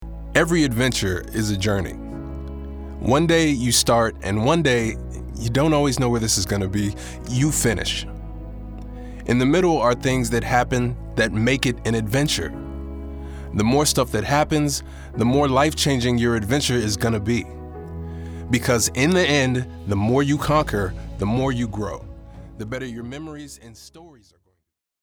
Industrial VO
Voice Over